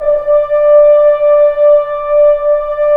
Index of /90_sSampleCDs/Roland L-CD702/VOL-2/BRS_F.Horns 1/BRS_FHns Ambient